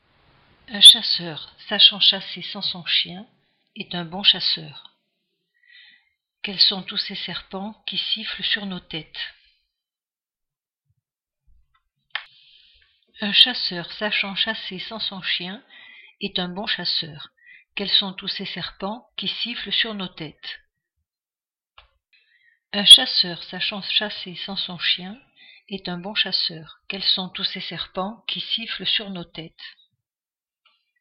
Gare à vos oreilles car j’ai choisi des phrases particulièrement chuintantes et sifflantes.
A l’écoute de votre fichier test, je pense que le microphone est placé un peu trop en avant et reçoit le souffle des sifflantes. A moins qu’il ne soit pas de bonne qualité et capte trop ces fréquences ; mais le reste de l’enregistrement me parait propre.
Après une réécoute pus attentive au casque, le microphone parait très sensible ; peut-être un peu trop (captation de l’écho de la pièce).